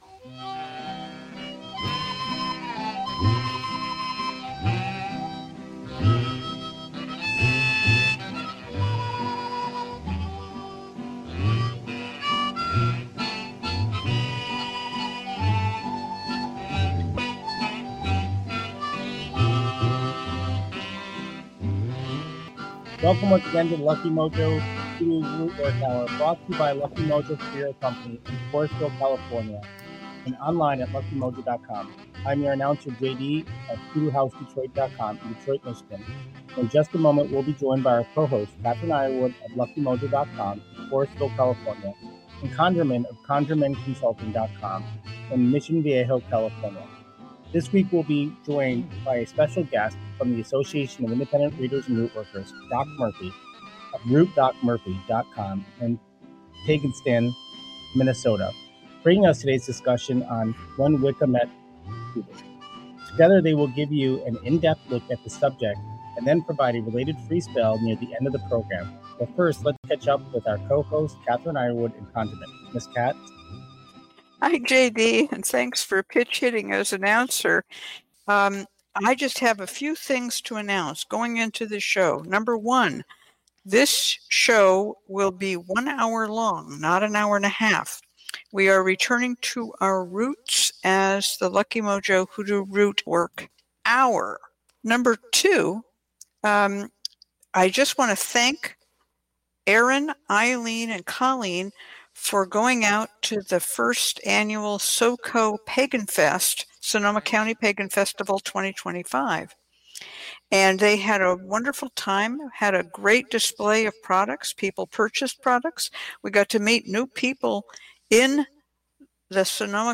We got our sound problems sorted and we are here ... except NO ANNOUNCEr.